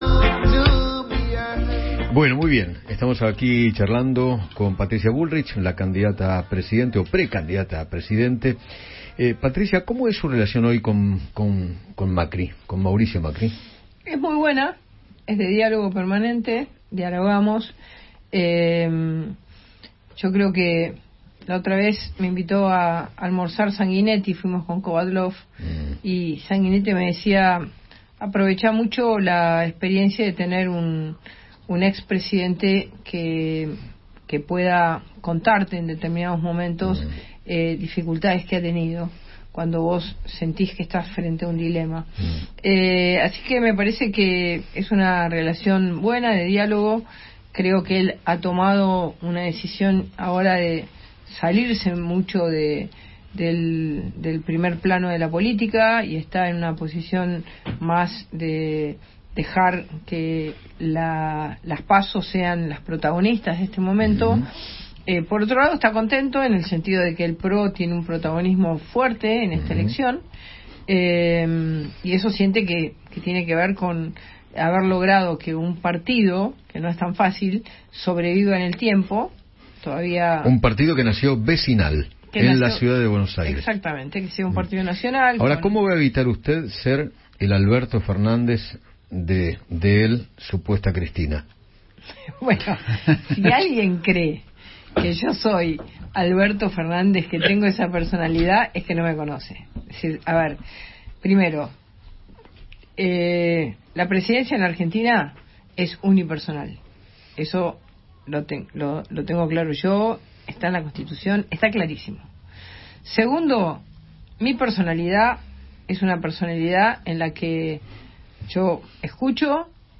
Patricia Bullrich, precandidata a presidente de la Nación por Juntos por el Cambio, dialogó con Eduardo Feinmann y se refirió a los candidatos que incluyó en su lista.